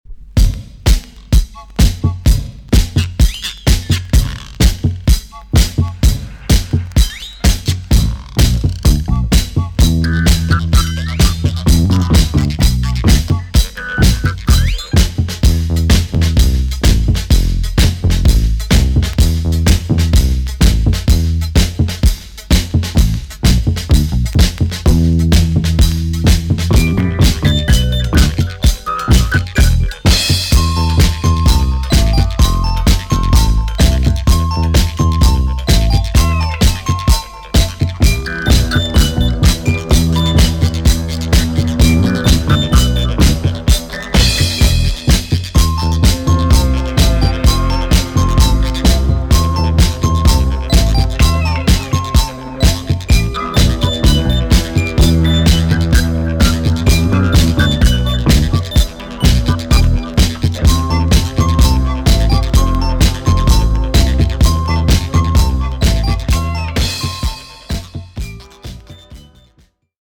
EX 音はキレイです。